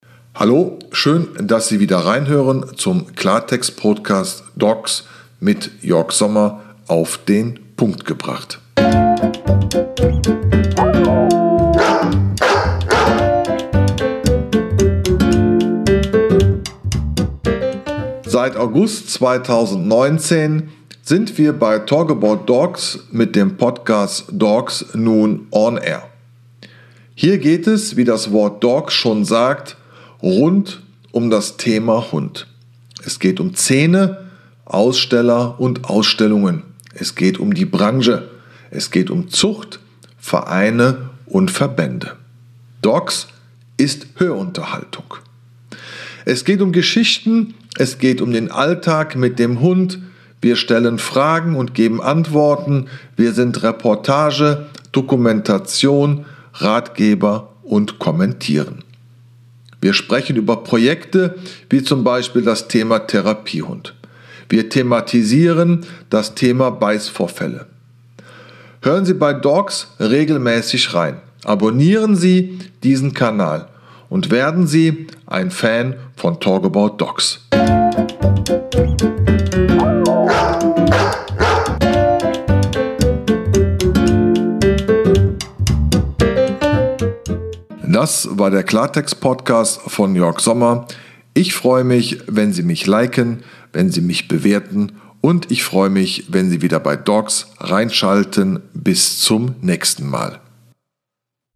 Mit Kölner Schnauze und so herrlich direkt
Mit Gebell und Stimmen aus der Hundeszene gibt es besondere Einblicke zum Thema: Alltagssituationen, Kennel und Zucht, Reisepodcast sowie Welpenzauber.